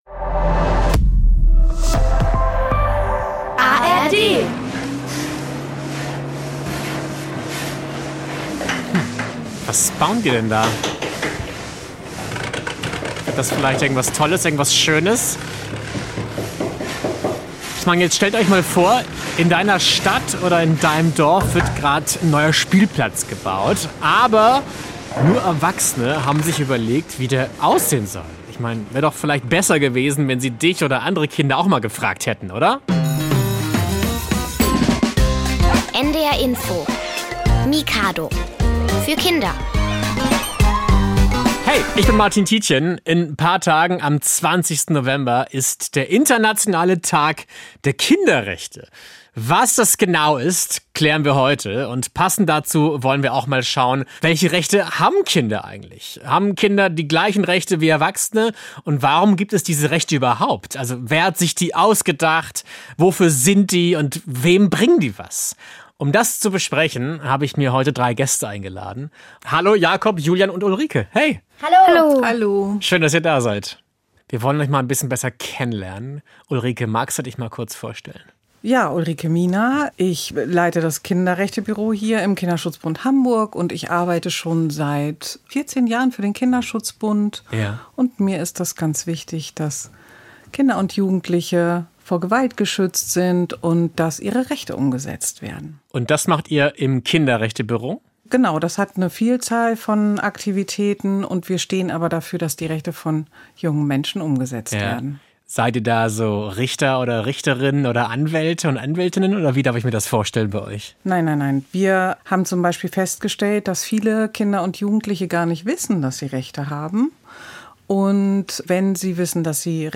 Darüber sprechen unsere Gäste im Mikado-Studio.